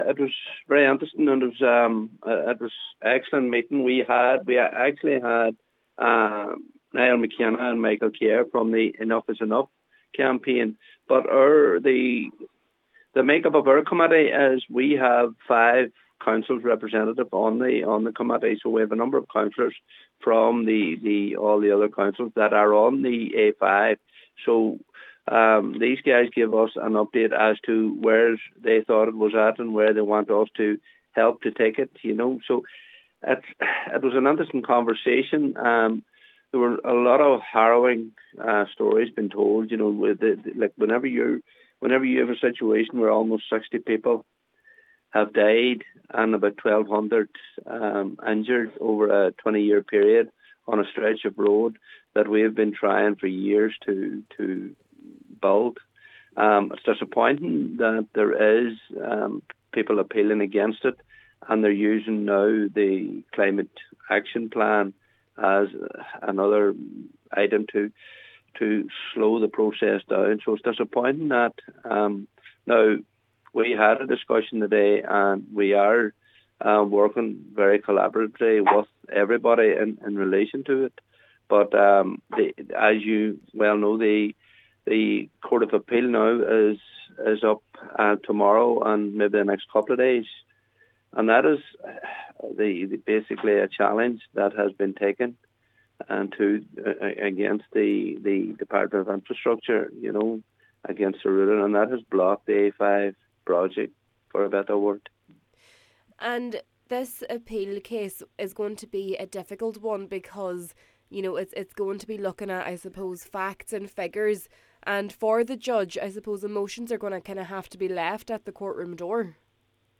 Cllr Paul Canning, Chairperson of the committee, says it’s important that those who died are at the core of this development: